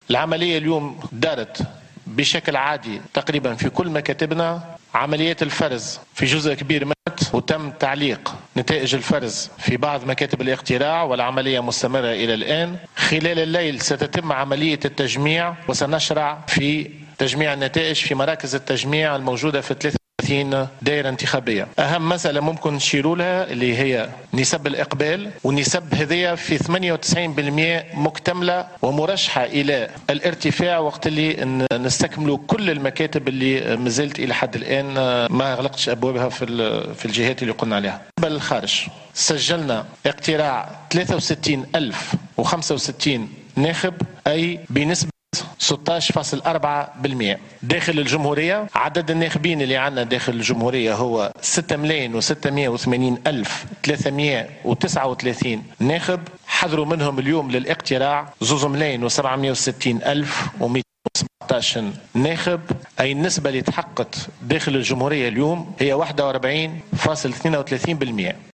وأفاد بفون في ندوة صحفية مساء اليوم الأحد بالمركز الاعلامي بقصر المؤتمرات بالعاصمة ان هذه النسب مرشحة للارتفاع بعد استكمال غلق بقية مراكز الاقتراع، موضحا ان 98 بالمائة من مكاتب الاقتراع داخل تونس وخارجها أغلقت أبوابها وانطلقت بها عمليات الفرز، باستثناء مكاتب دائرة الامريكيتين وباقي دول أوروبا (كندا وأمريكا).